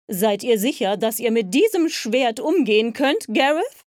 Junge Liebe - Lady Lynette: